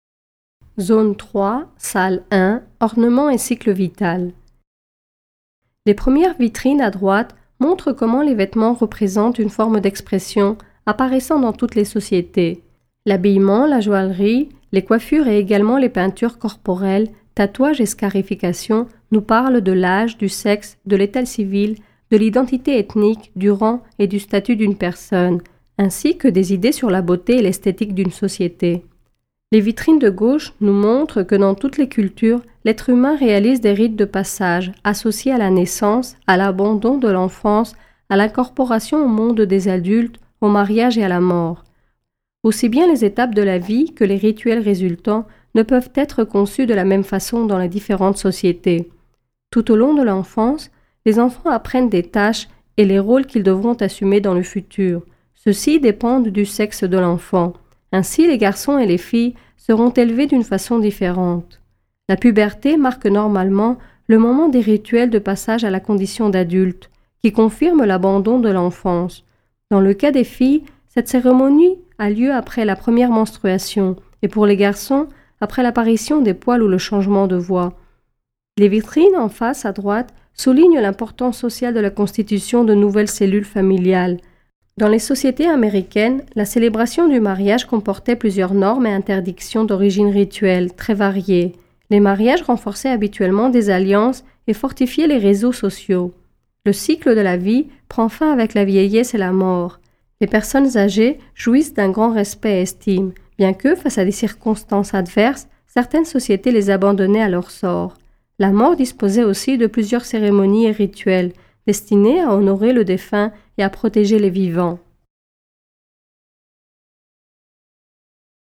Audioguides par pièces